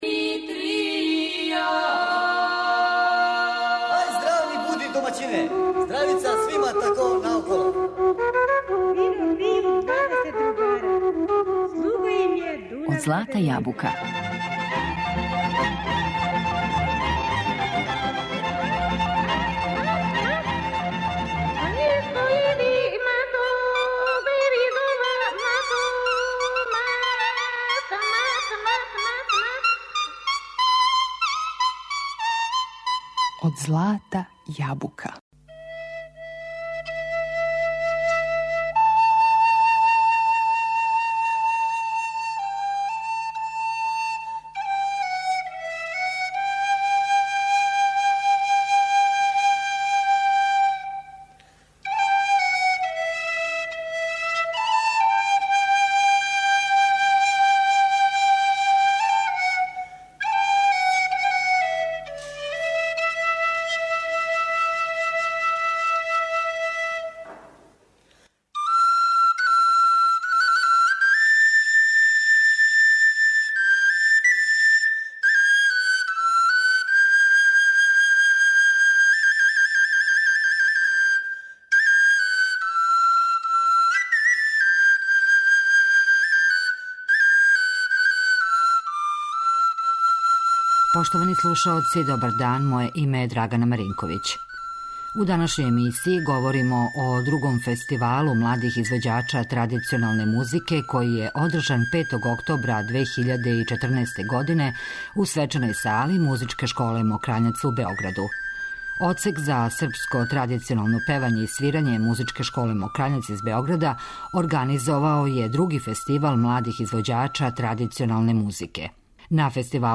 Учествовали су млади из целе Србије, узраста до деветнаест година, који су презентовали вештину технике свирања традиционалних инструмената, као и умеће интерпретирања народних песама.